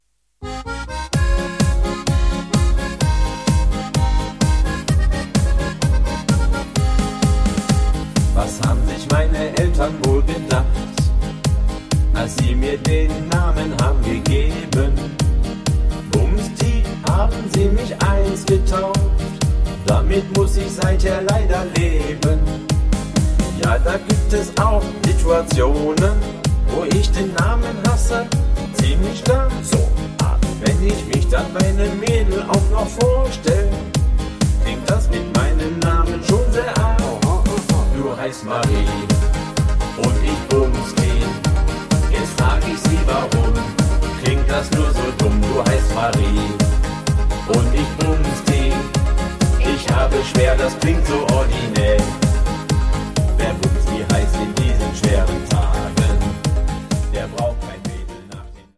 --- Schlager ---